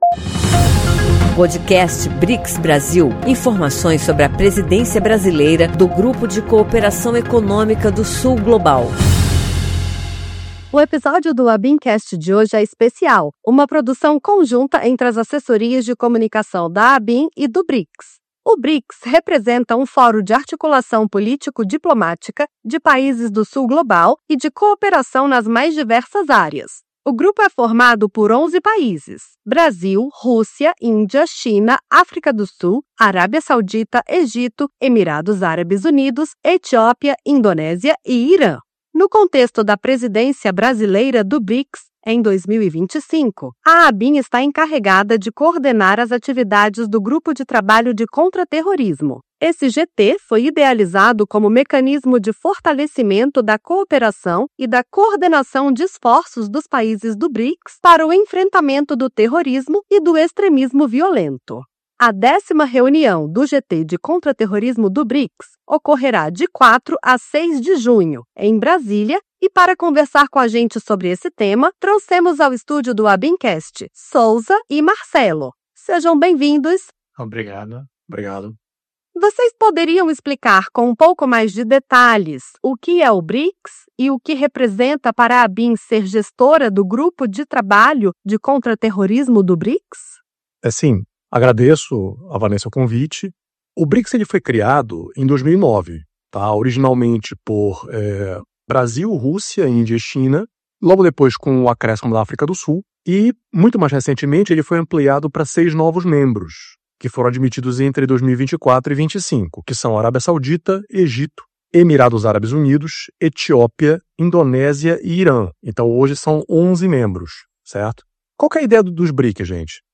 Os dois oficiais de Inteligência entrevistados também explicam o novo Protocolo de Prevenção de Ameaças do Extremismo Violento, a ser lançado este mês pela ABIN.